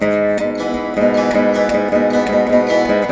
154B VEENA.wav